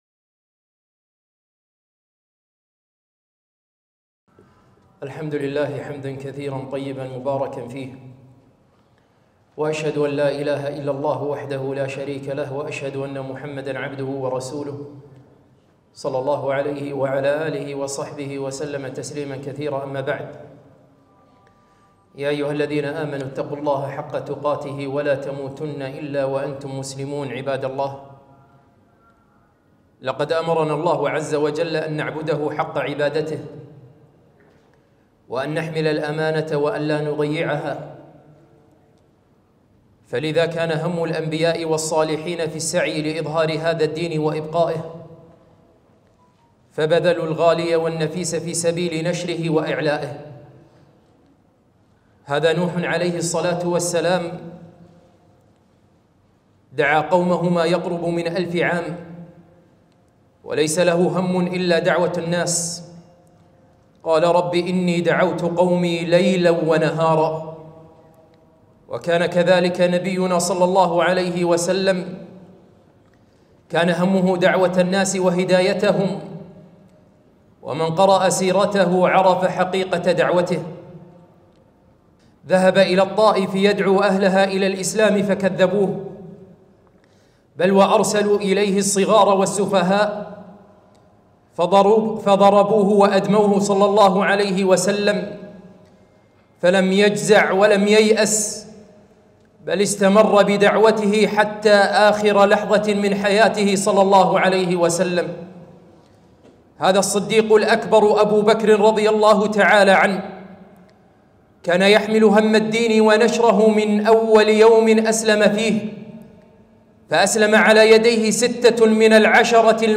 خطبة - ما هو همك ؟